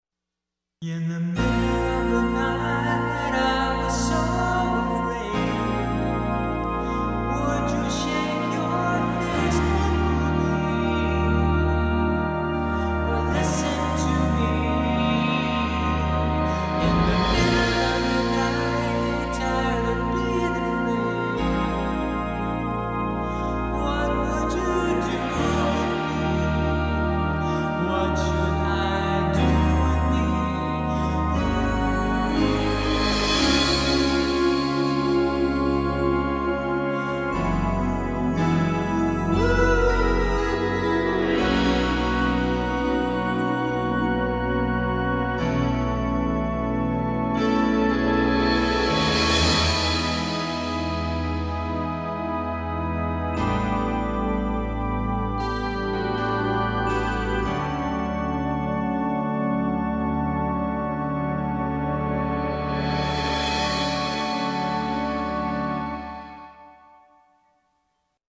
keyboard trx and lead vocal
cymbals